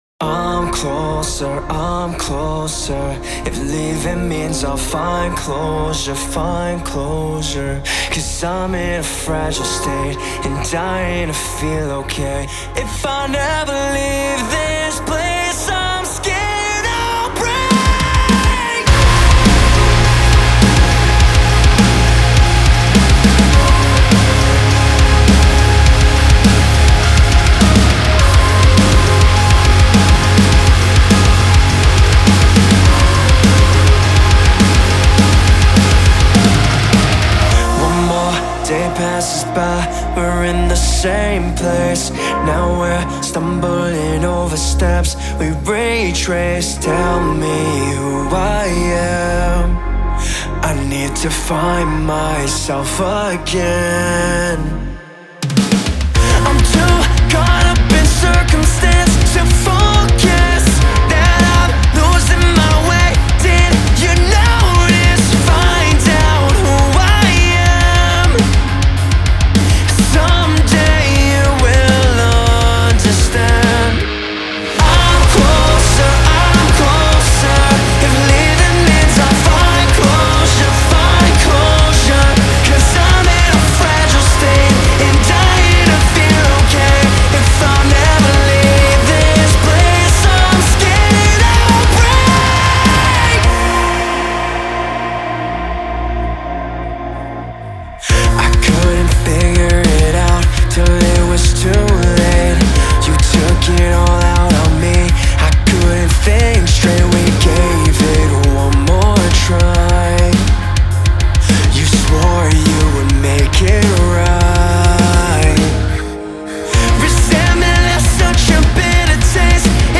آلترناتیو راک
آلترناتیو متال